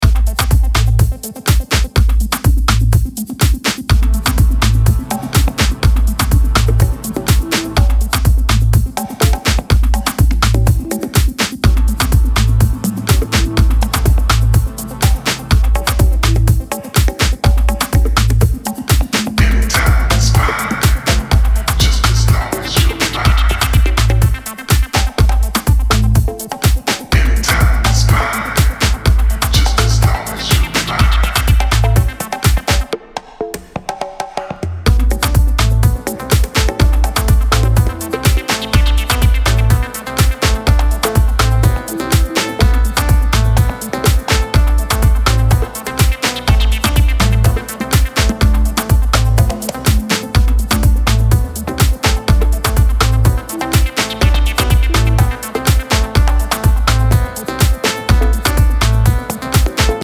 Genre Afro House